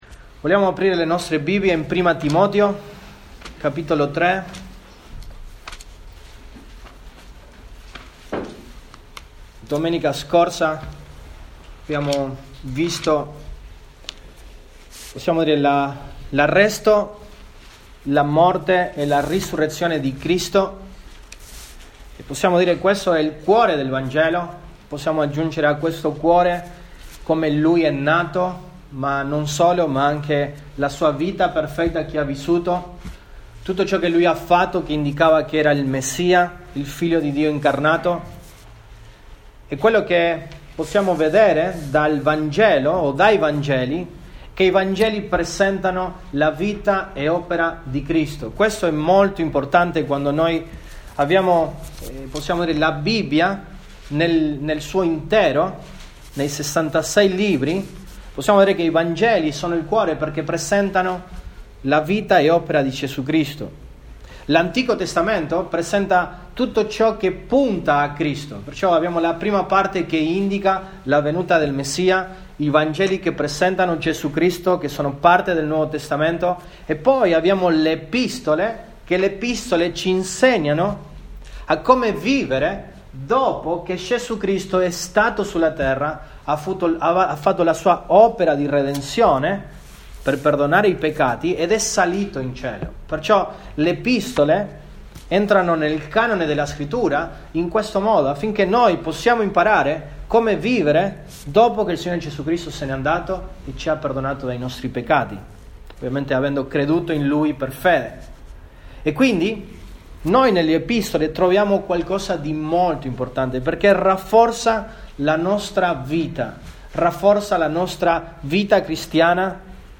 Sermoni